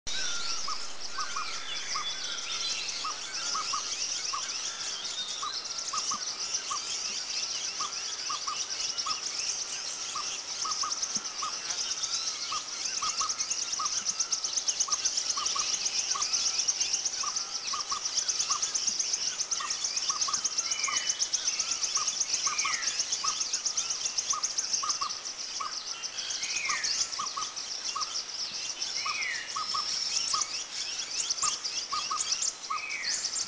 Collared Owlet
With agitated small birds.
Glaucidium brodiei
CollaredOwlet+agitatedBirds.mp3